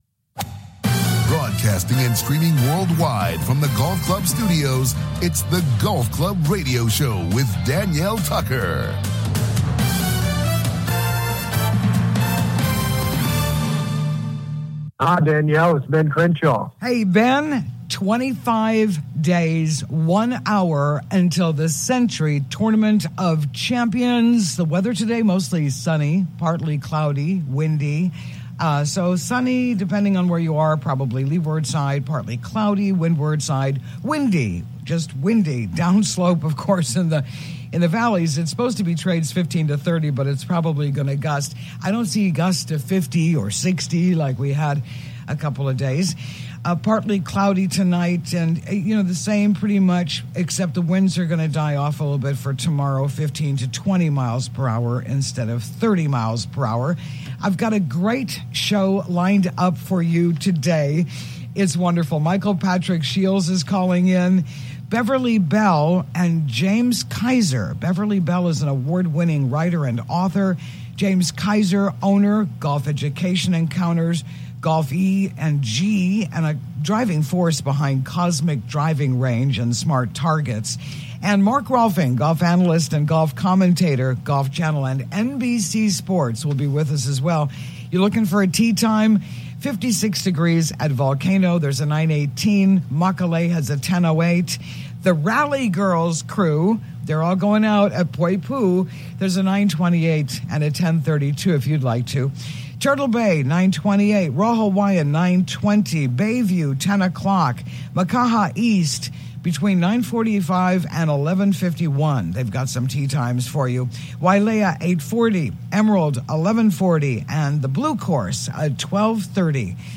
COMING TO YOU LIVE FROM THE GOLF CLUB STUDIOS ON LOVELY OAHU�s SOUTH SHORE � WELCOME INTO THE GOLF CLUB HOUSE!